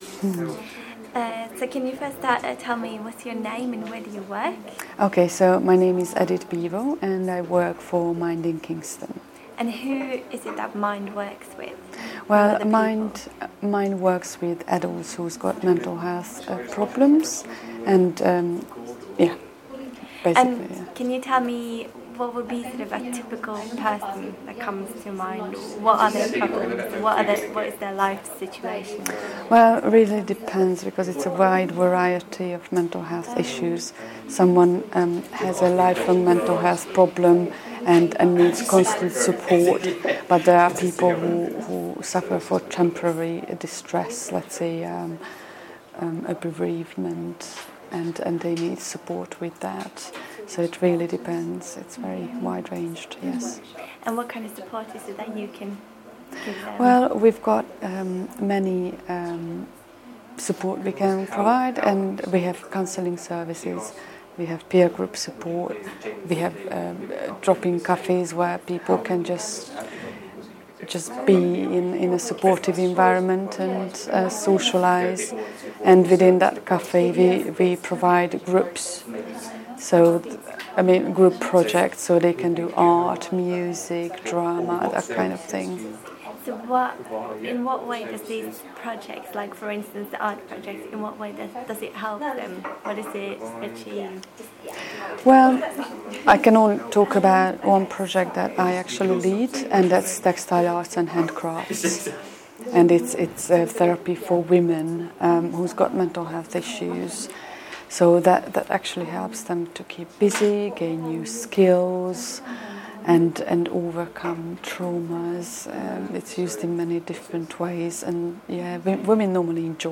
Interview about their services